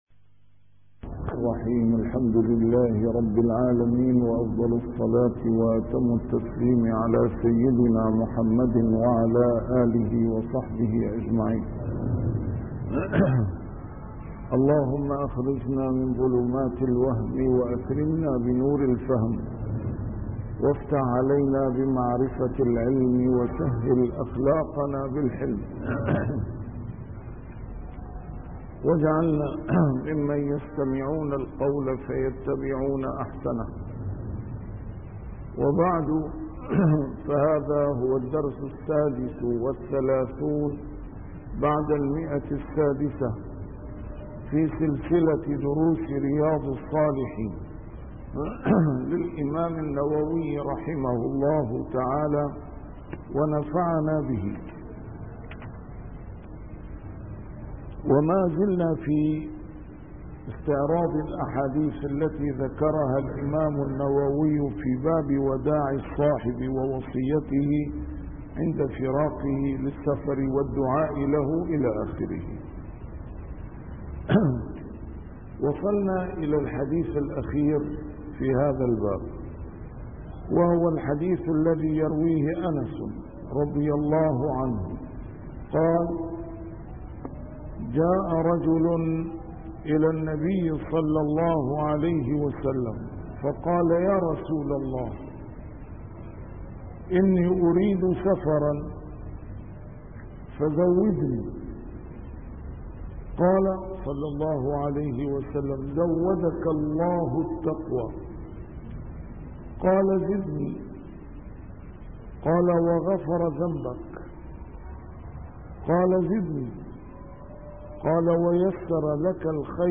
A MARTYR SCHOLAR: IMAM MUHAMMAD SAEED RAMADAN AL-BOUTI - الدروس العلمية - شرح كتاب رياض الصالحين - 636- شرح رياض الصالحين: وداع الصاحب